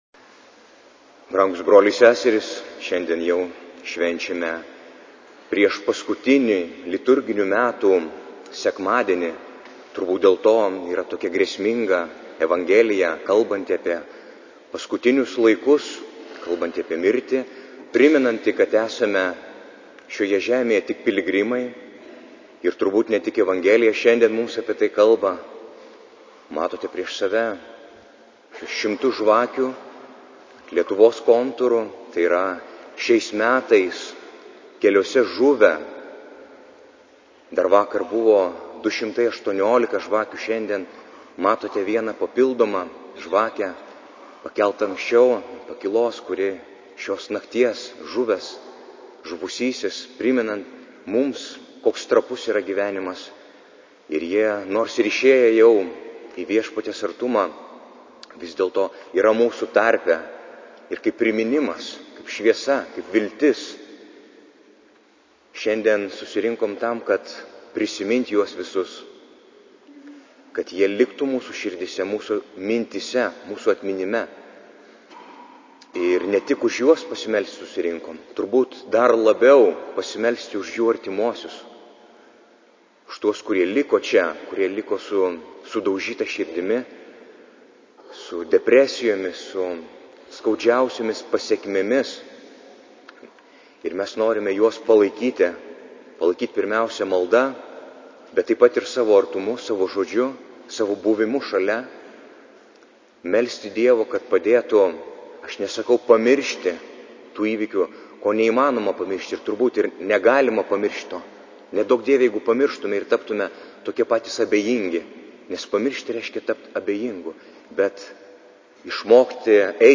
Audio pamokslas: